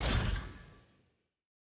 sfx-exalted-chase-sweet-button-click.ogg